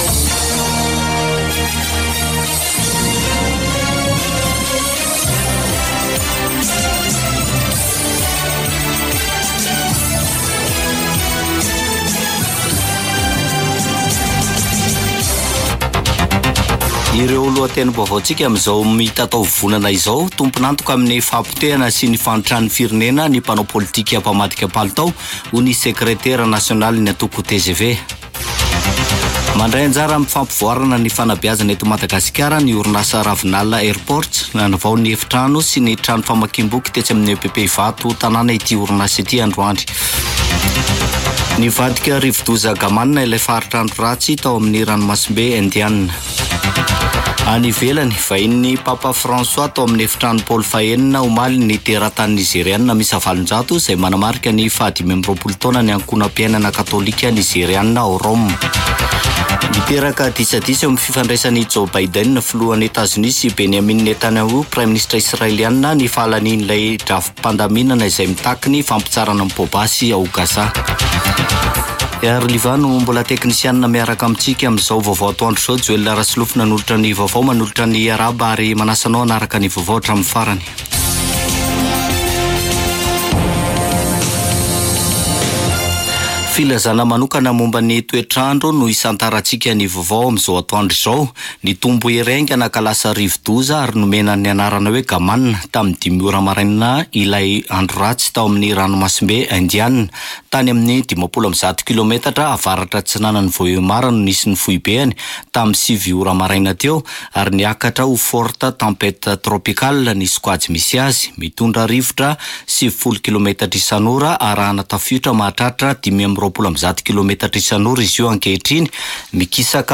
[Vaovao antoandro] Talata 26 marsa 2024